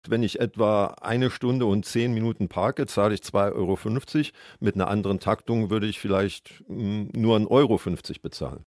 Das hat uns Stadtkämmerer Wolfgang Cavelius im Radio Siegen-Interview gesagt. Er favorisiert eine andere Taktung der Automaten.